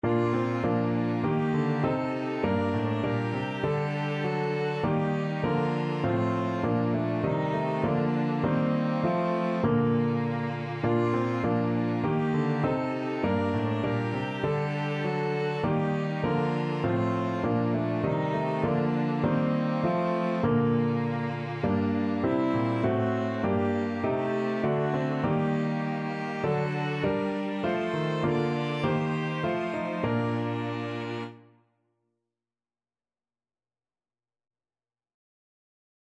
Violin
Clarinet
Cello
4/4 (View more 4/4 Music)